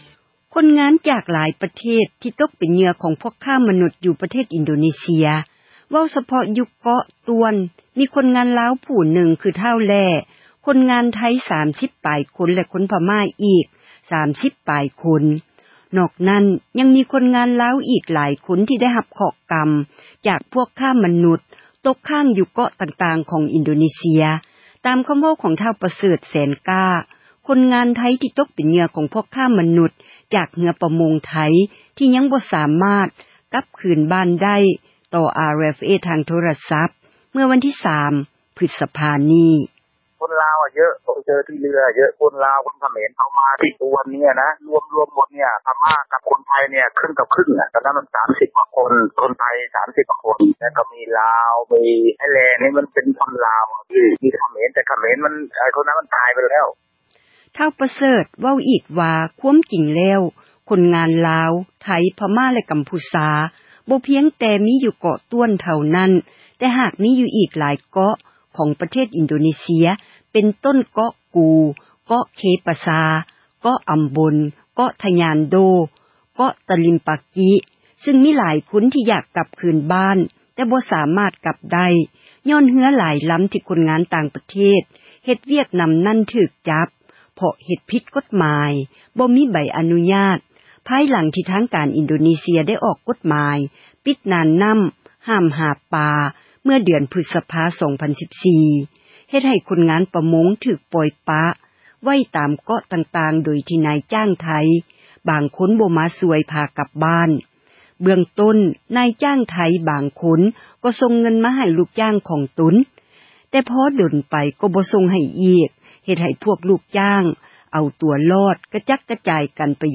ຕໍ່ RFA ທາງໂທຣະສັບ ເມື່ອວັນທີ 3 ພຶສຈິກາ ນີ້: